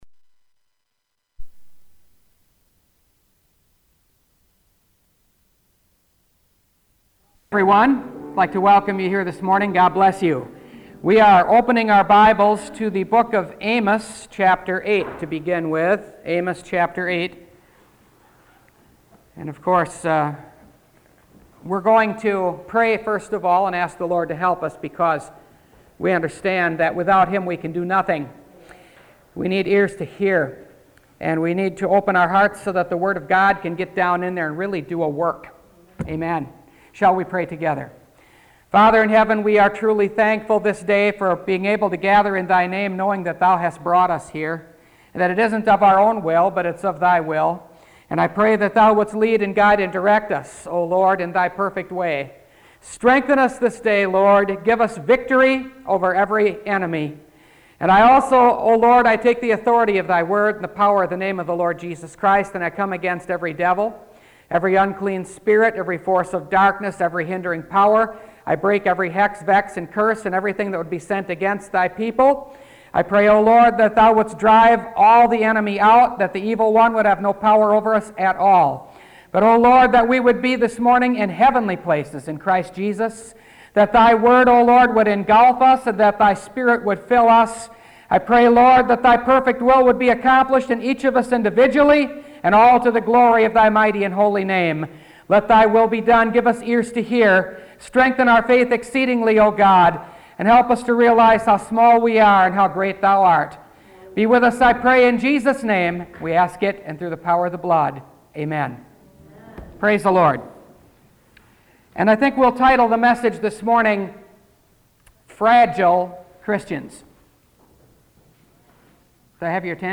Fragile Christians – Last Trumpet Ministries – Truth Tabernacle – Sermon Library